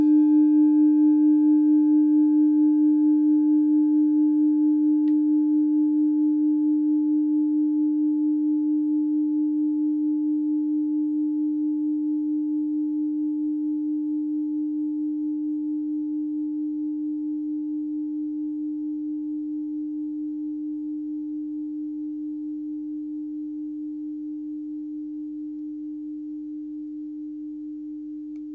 Kleine Klangschale Nr.32 Bengalen Planetentonschale: Wasserfrequenz
Kleine Klangschale Nr.32
Klangschale-Gewicht: 520g
Klangschale-Durchmesser: 13,5cm
Sie ist neu und ist gezielt nach altem 7-Metalle-Rezept in Handarbeit gezogen und gehämmert worden.
Die Wasserfrequenz liegt bei 178,81 Hz und dessen tieferen und höheren Oktaven. In unserer Tonleiter ist das in der Nähe vom "Fis".
kleine-klangschale-32.wav